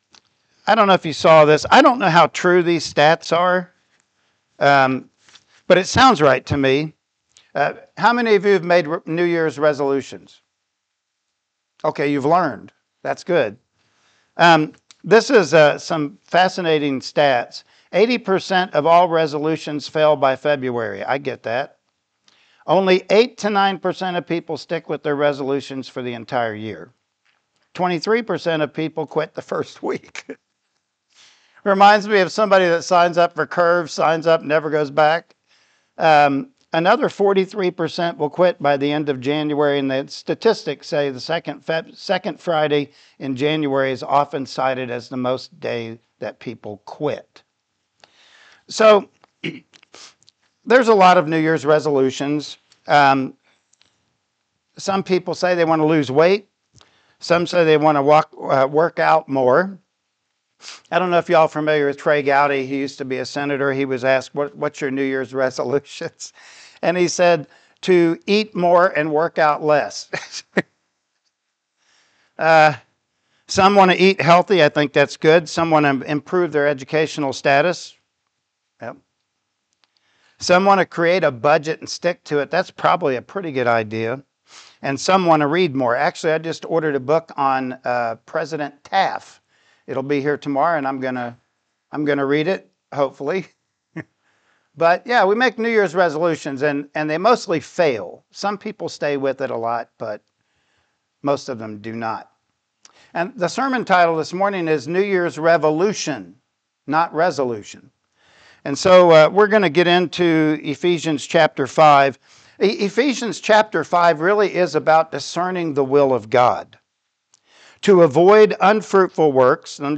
Ephesians 5:15-17 Service Type: Sunday Morning Worship Service Topics: Foolish and Wise